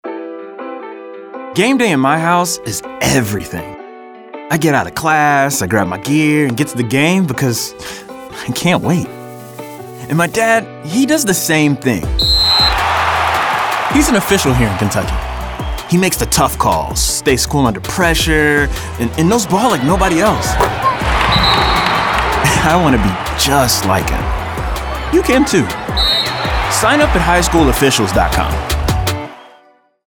25-26 Radio – Public Service Announcements